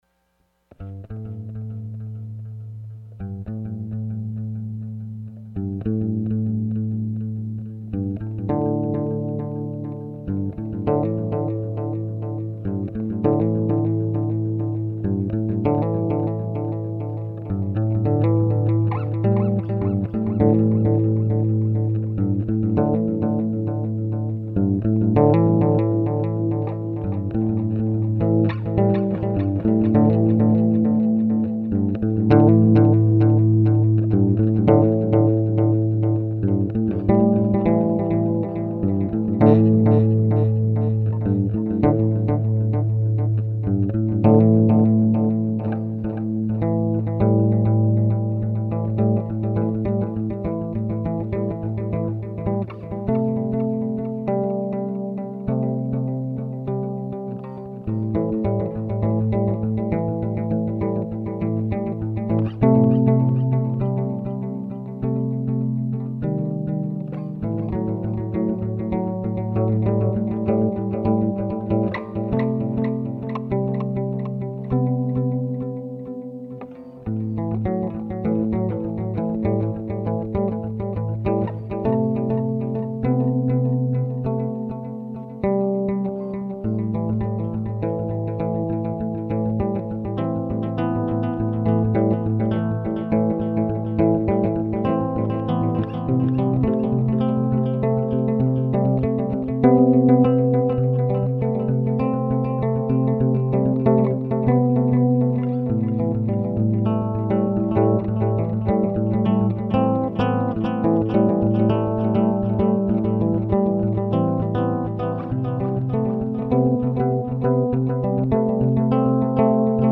small guitar sketch, very rough and recorded in the spur of the moment.